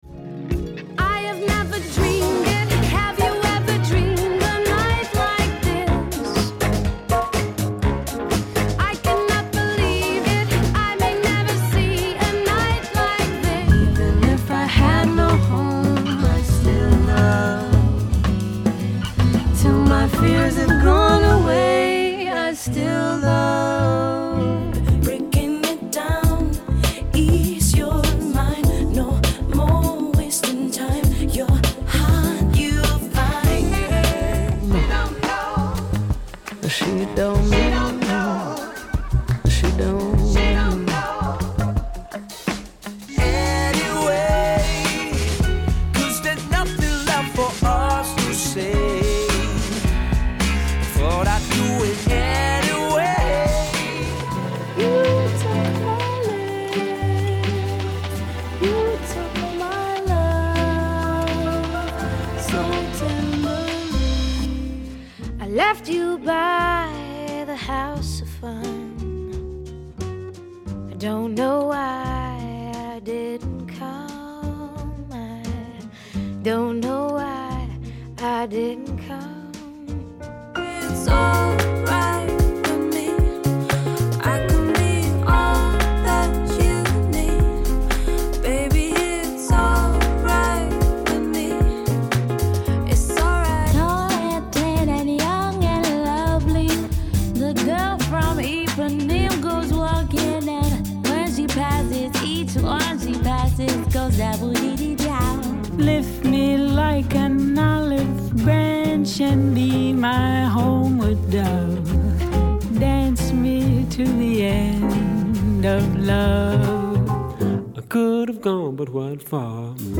Mixed Tempo